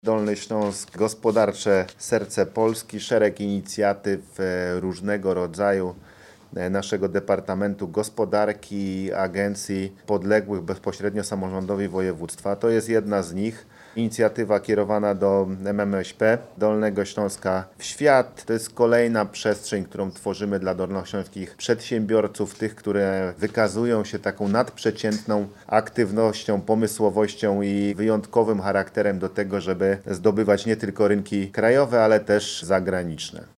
– To jedna z wielu inicjatyw proponowana przedsiębiorcom z regionu, którzy mogą podbić rynki zagraniczne, mówi Paweł Gancarz, marszałek Województwa Dolnośląskiego.